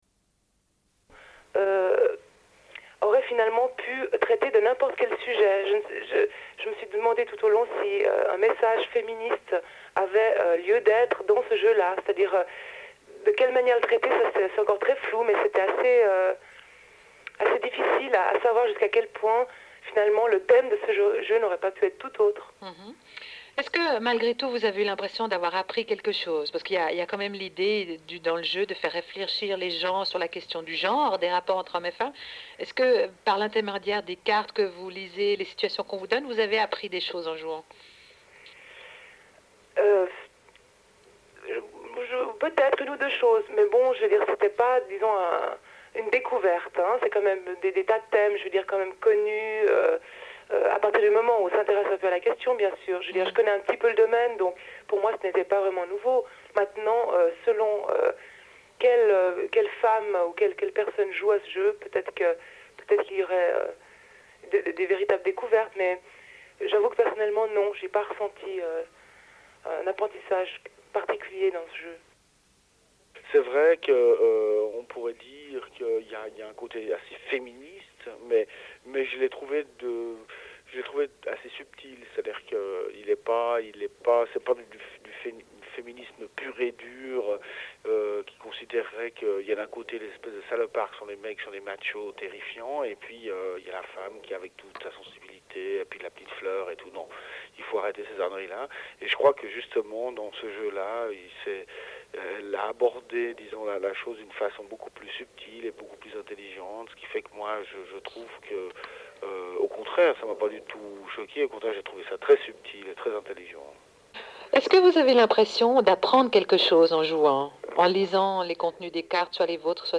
Une cassette audio, face A31:18